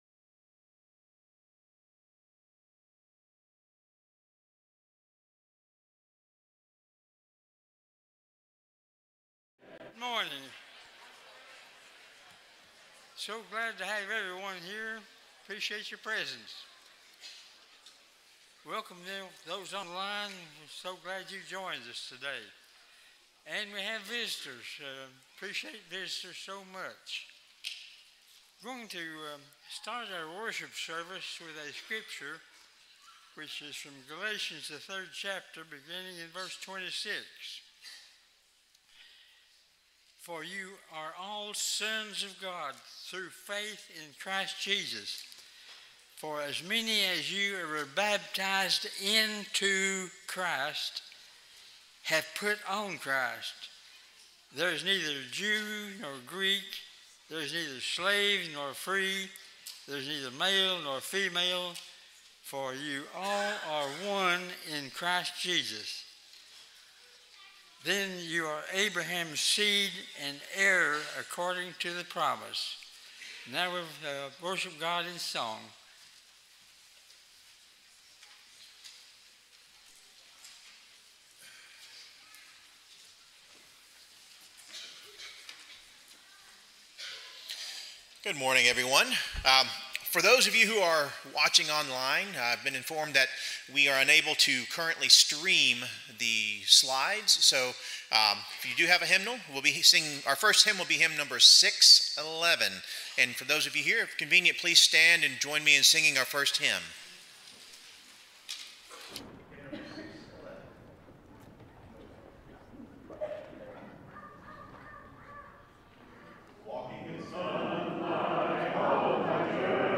Matthew 22:14, English Standard Version Series: Sunday AM Service